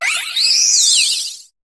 Cri de Rubombelle dans Pokémon HOME.